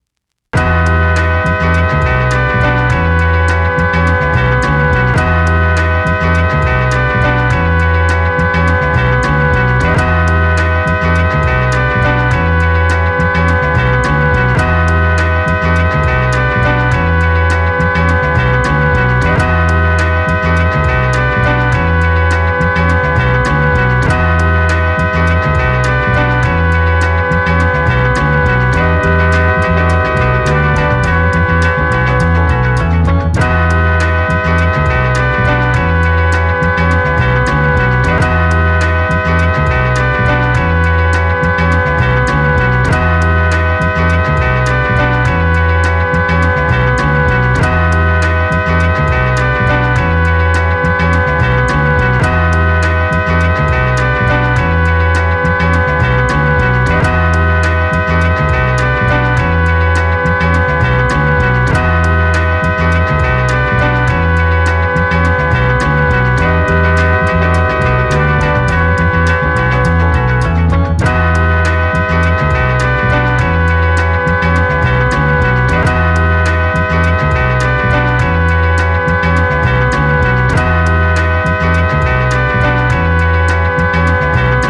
Psykadelisk sample - typ blåsinstrument och trummor